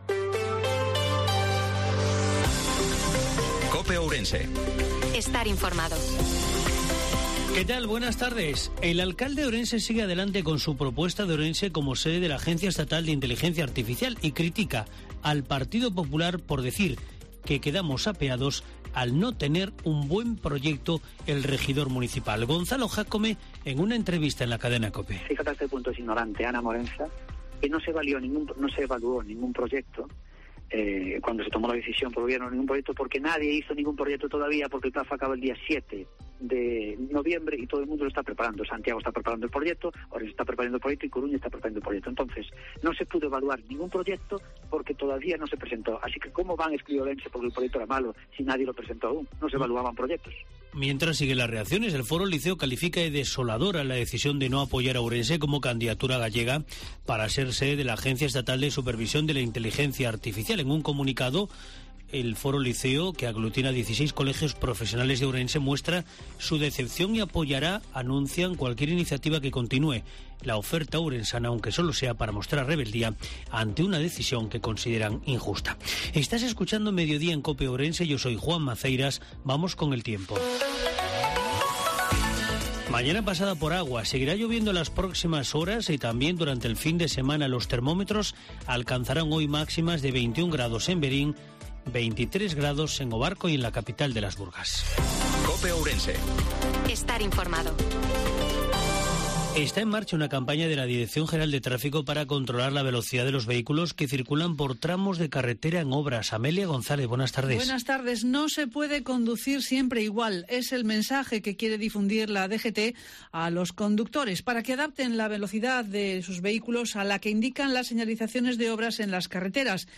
INFORMATIVO MEDIODIA COPE OURENSE-28/10/2022